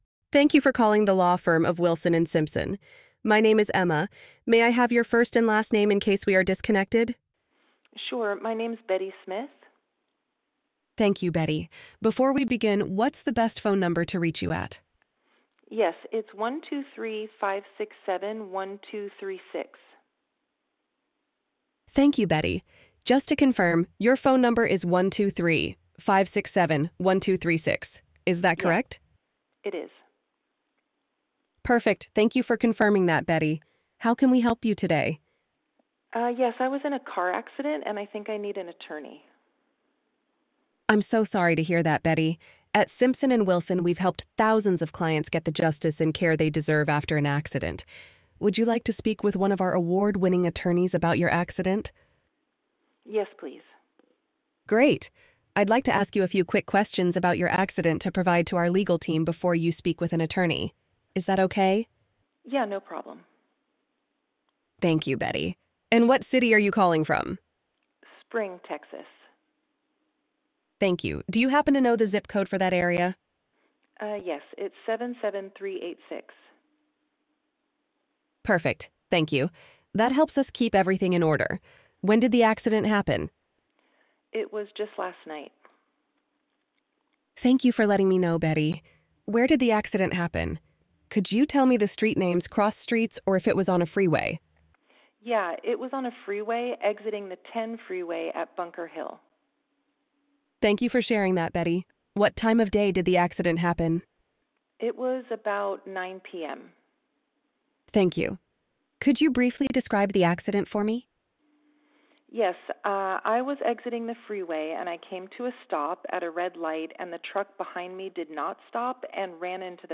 Listen to a Sample MVA Intake Call Listen to a Sample MVA Intake Call Why Top Law Firms Choose BrightVoice Why Top Law Firms Choose BrightVoice 24/7 Availability 24/7 Availability 24/7 Availability BrightVoice never sleeps.
Human-Like Human-Like Human-Like Our AI agents are trained with legal intake best practices and deliver natural, professional conversations that reflect your firm’s tone and brand.